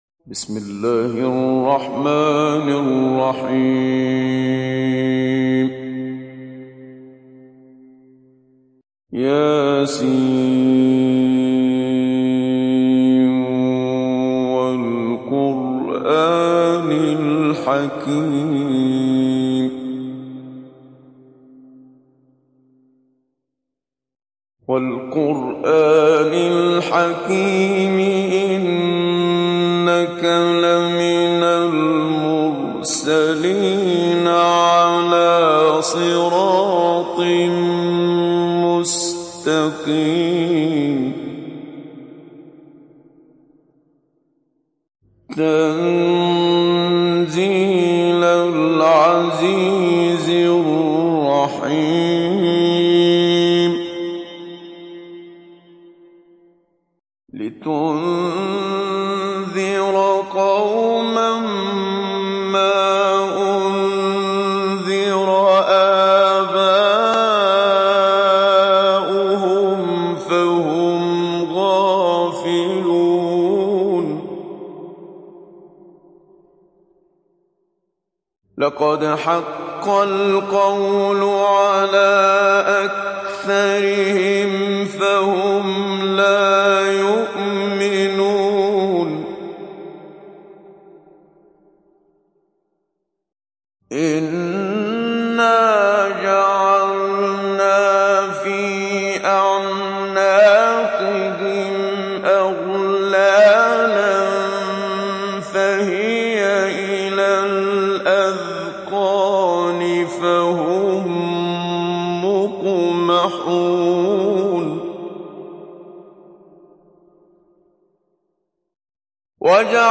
İndir Yasin Suresi Muhammad Siddiq Minshawi Mujawwad